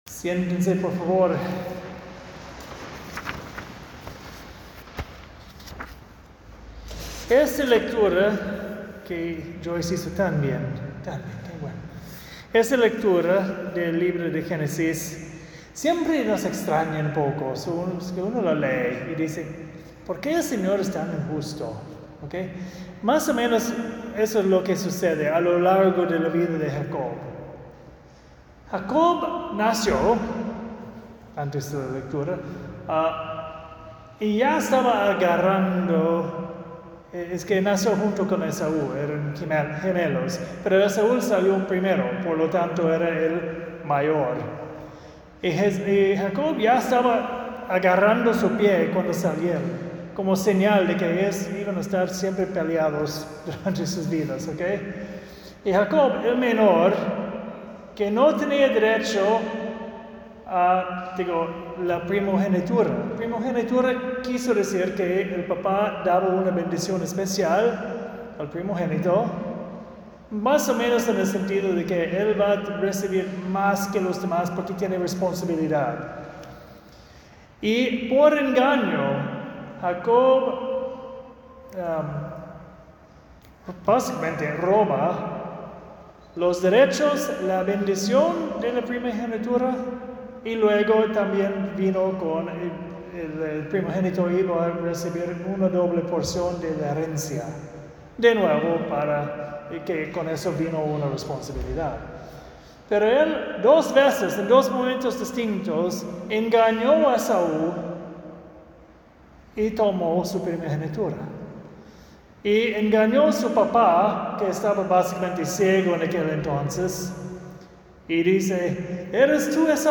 homily